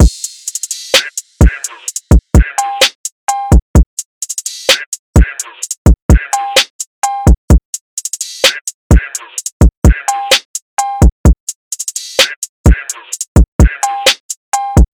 DRUM LOOPS
Levels (128 BPM – Bbm)
UNISON_DRUMLOOP_Levels-128-BPM-Bbm.mp3